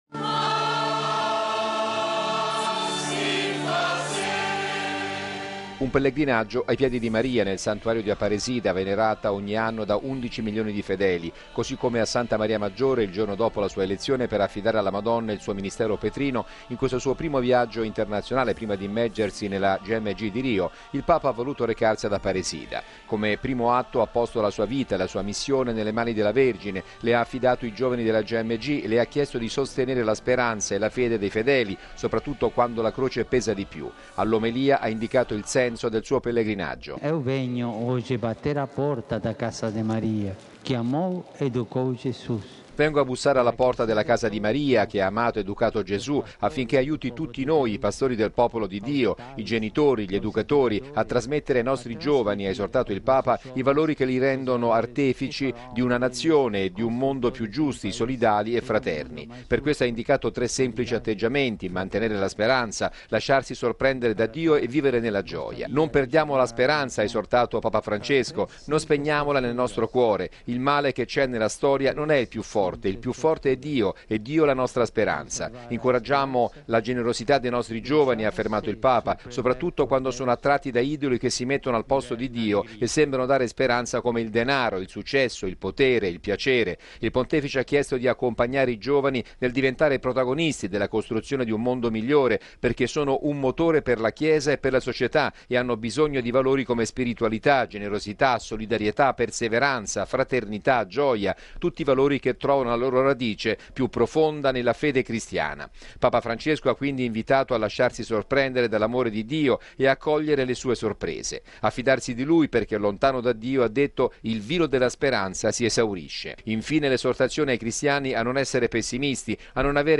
Ad Aparecida oltre 200 mila fedeli sotto una pioggerellina insistente si sono stretti intorno al Papa.